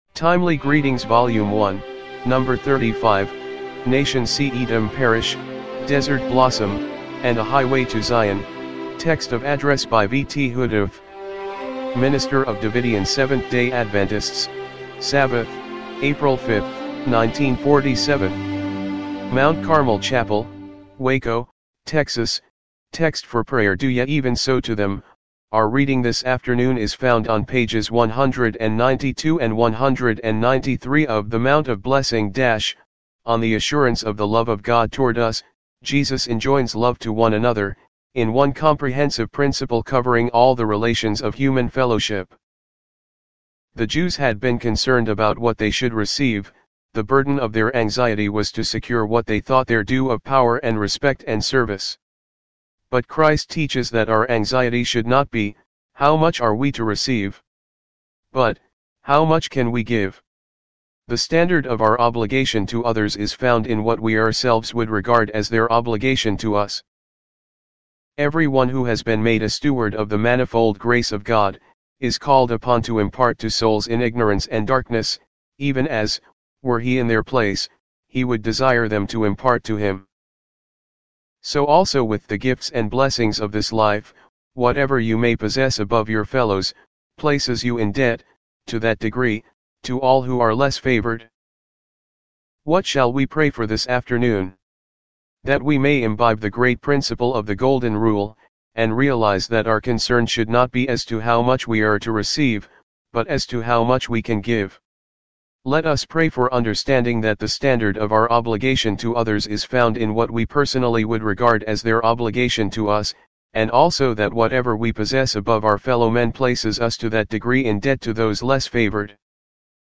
timely-greetings-volume-1-no.-35-mono-mp3.mp3